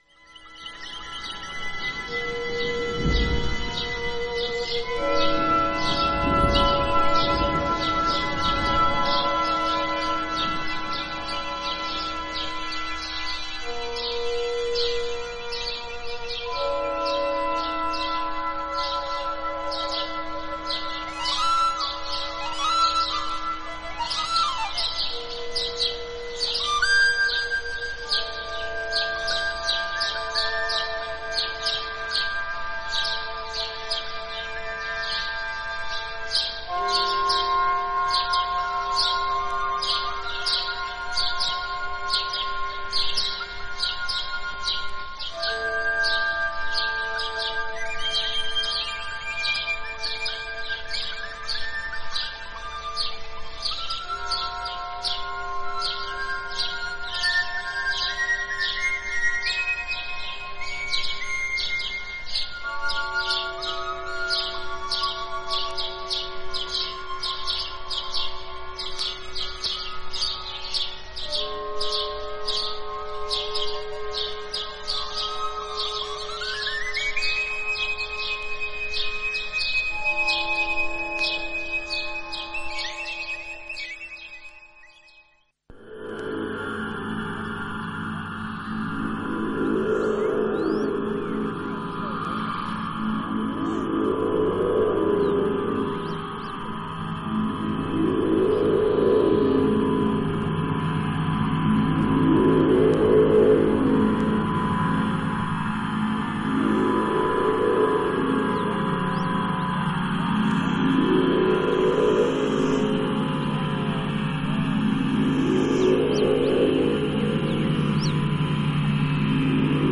エレクトロニクスとフィールド・レコーディングが織りなす、壮大なニューエイジ/アンビエント作品です。